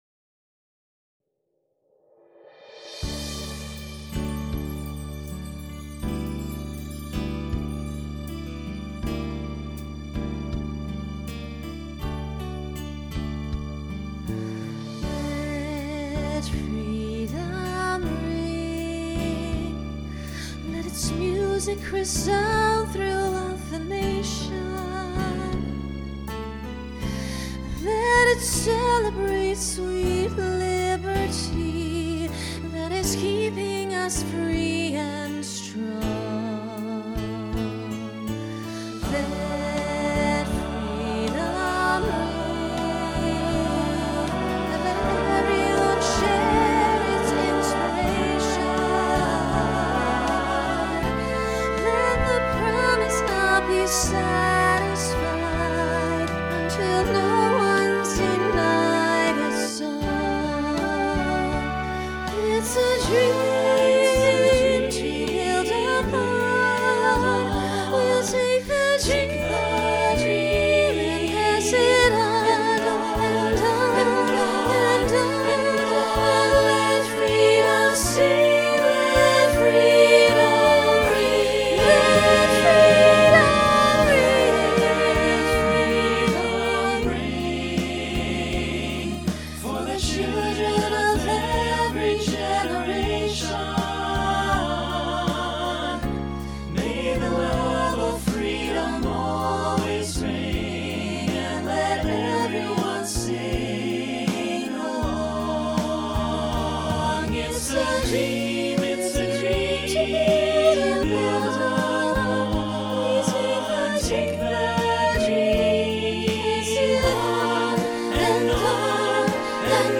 Voicing SATB Instrumental combo Genre Pop/Dance
Function Ballad